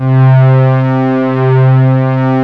PAD 1.wav